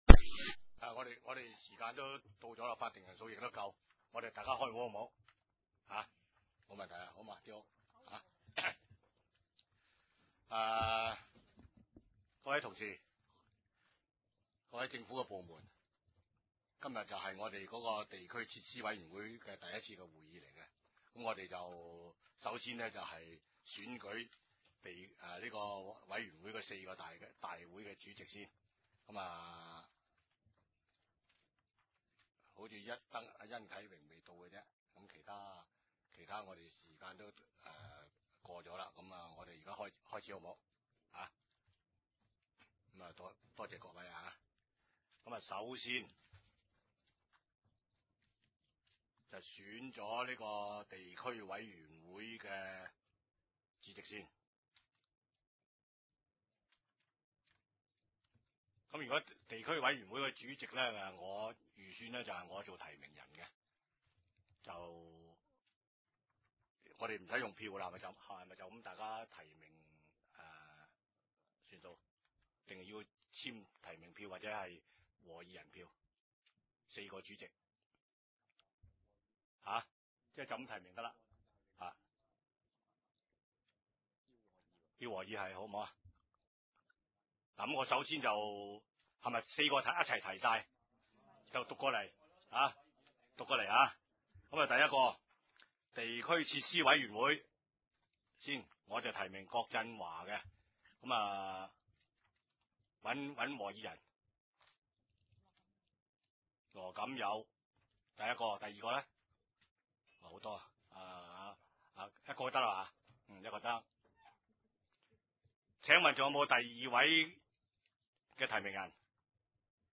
二零零八年一月十一日 第三届深水埗区议会 地区设施委员会第一次会议修订议程 日期： 二零零八年一月十一日 ( 星期五 ) 时间： 下午二时十五分至三时正 地点： 九龙长沙湾道 303 号长沙湾政府合署 4 字楼 深水埗区议会会议室 议 程 讨论时间 1.